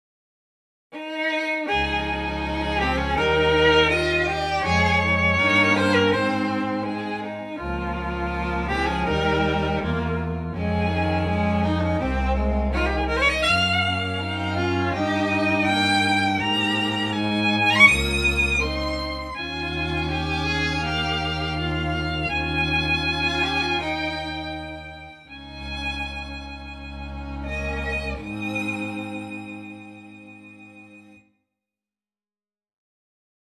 アタック音の輪郭をはっきりさせる効果があります。
Accent(Acc.Type)も併用してアタック音の大きさを調整し、弓と弦の擦れを強調しました。
目の前で演奏されているかの様な、素のリアリティー、弦のアクの強さがありますね。